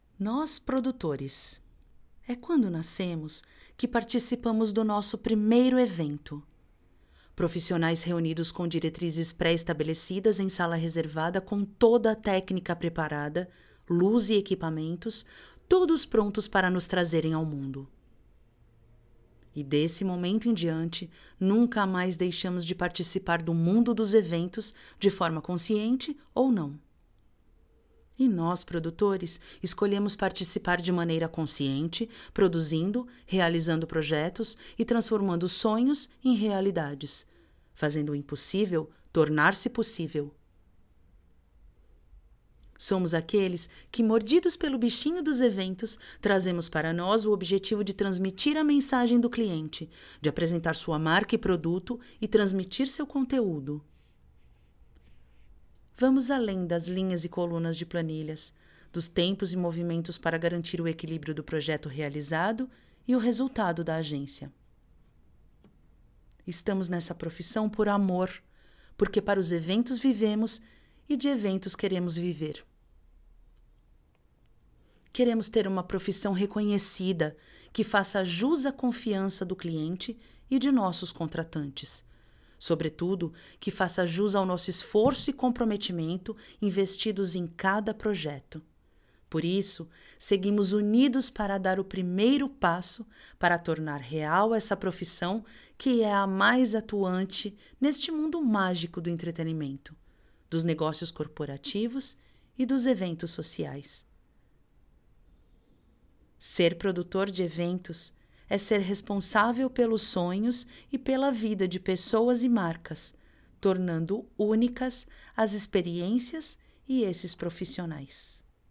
游戏场景解说